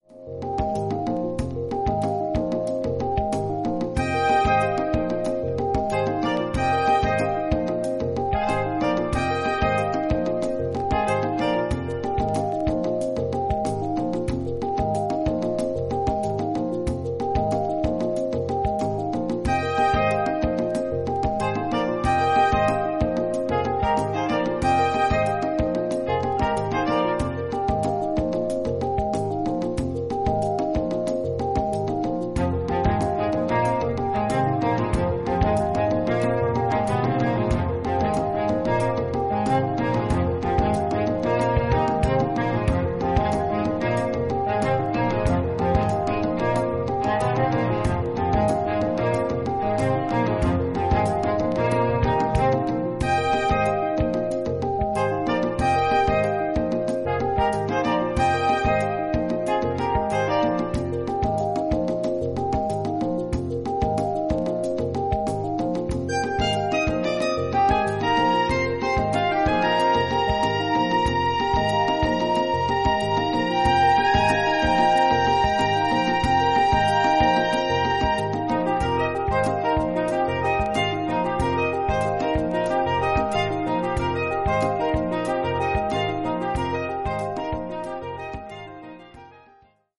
浮遊感溢れるマリンバ調のリフに導かれてファンキーなドラムが現れる